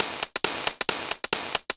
Index of /musicradar/ultimate-hihat-samples/135bpm
UHH_ElectroHatC_135-02.wav